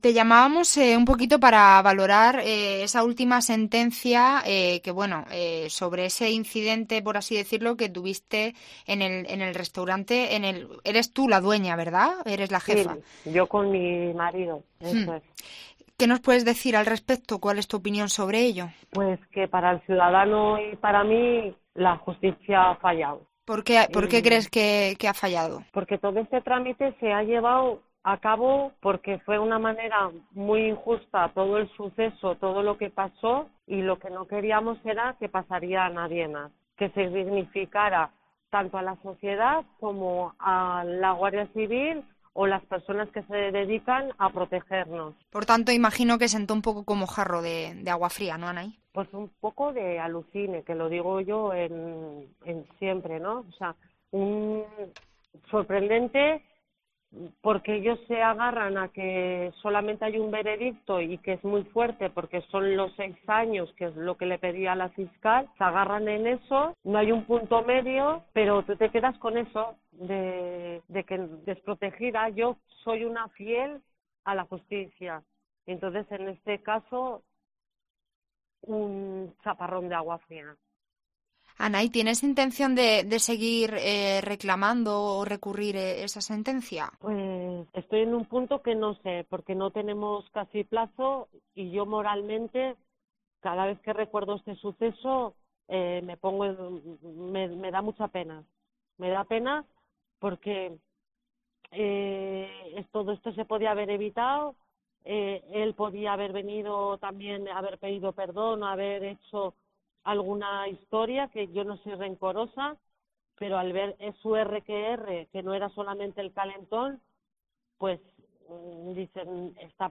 En COPE hemos hablado con ella y ha dado su versión de todo lo que ocurrió aquella noche